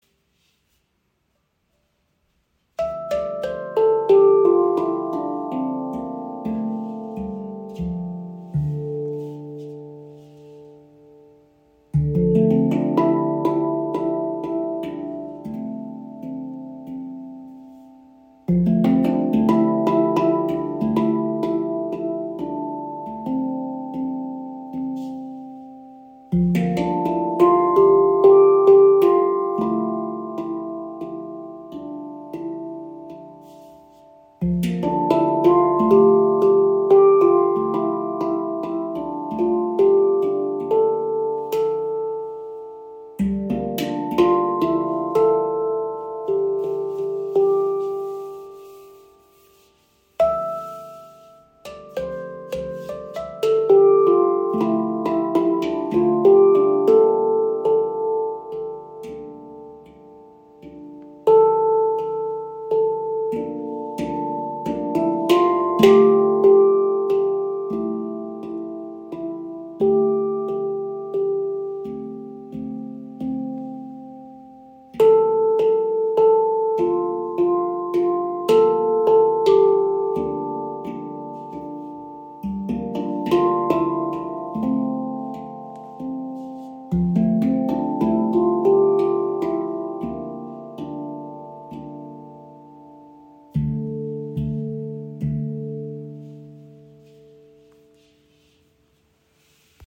Handpan Soulpan | D Kurd | 14  Klangfelder – lebendig & warm
SoulPan Handpans werden aus hochwertigem Edelstahl gefertigt und bieten einen klaren Klang mit langem Sustain.
• Icon D Kurd – emotionaler Charakter (D – (F G) A Bb C D E F G A C D E)
Warmer Klang, weiche Ansprache, ideal für Meditation, Klangreisen und intuitives Spiel.
Gefertigt aus hochwertigem Stainless Steel, entfaltet die SoulPan eine warme, harmonische Klangfarbe mit weicher Ansprache und langer Schwingung.
Ihr mollartiger Charakter wirkt warm, tiefgründig und zugleich offen.
Obertöne schwingen klar, der Grundton erdet.